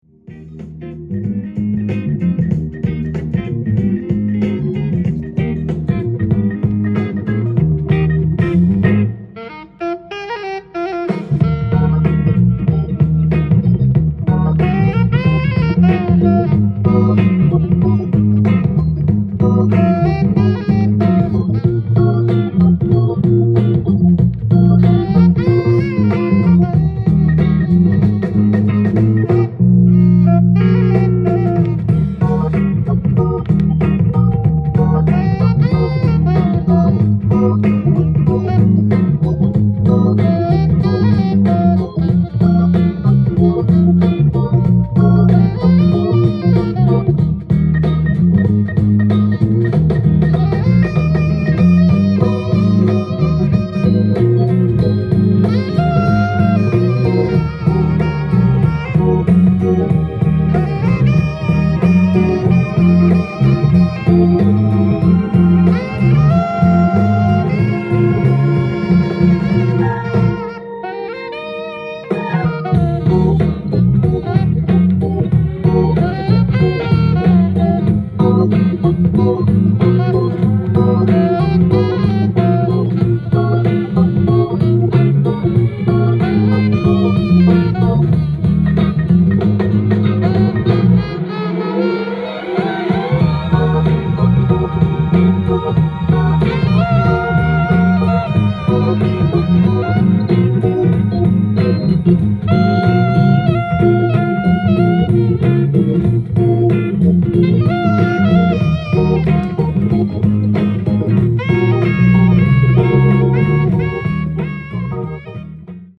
ジャンル：FUSION
店頭で録音した音源の為、多少の外部音や音質の悪さはございますが、サンプルとしてご視聴ください。